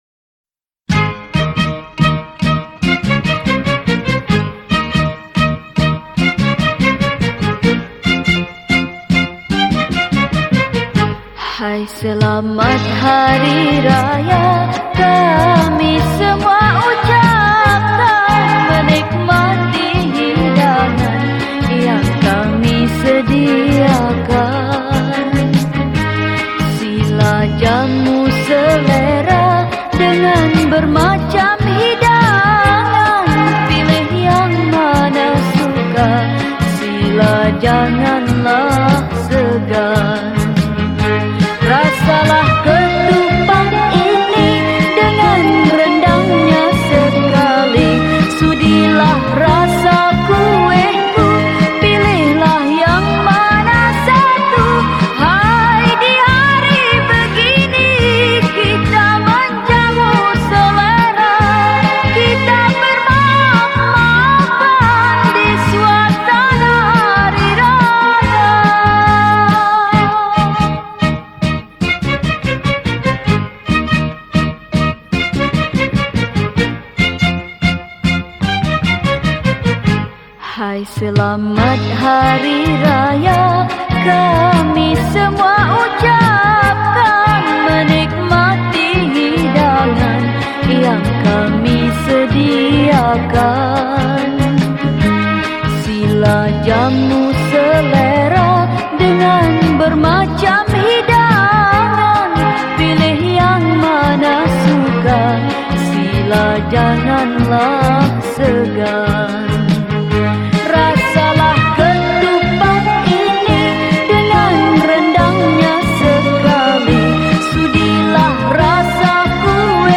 Lagu Hari Raya , Malay Songs